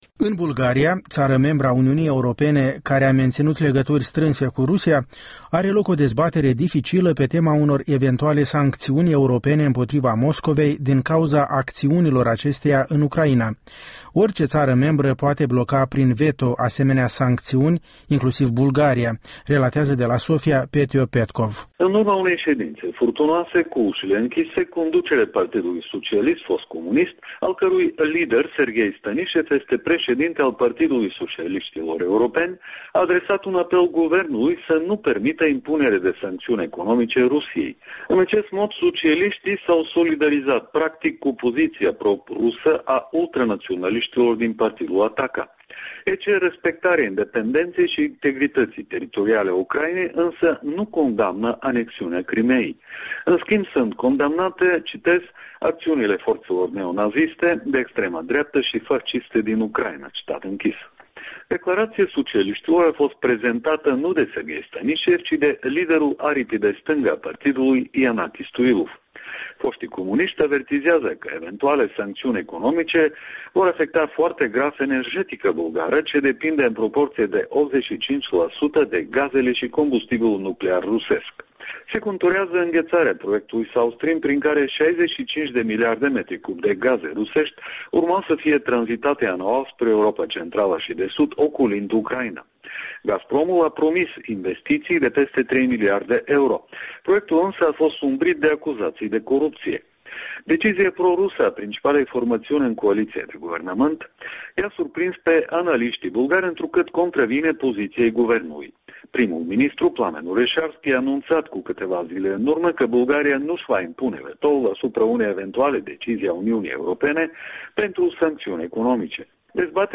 Bulgaria și criza ucraineană - o corespondență de la Sofia